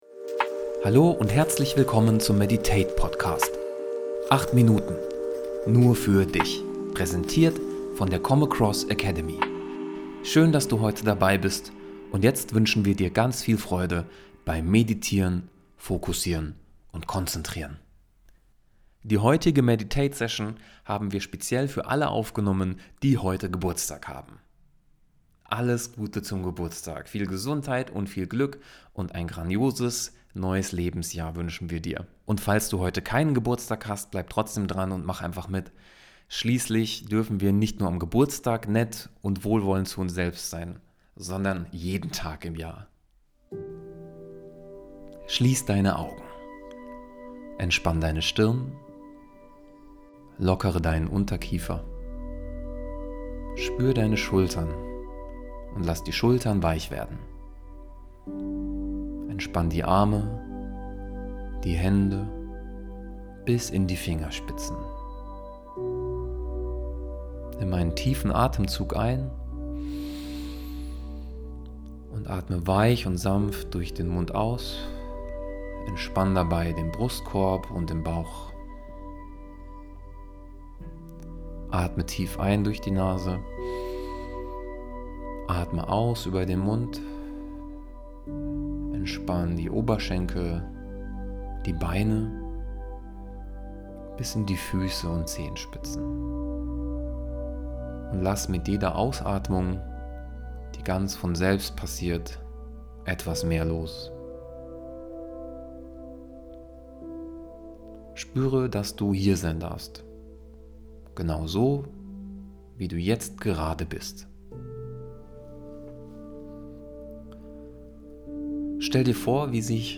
Geburtstagsmeditation.mp3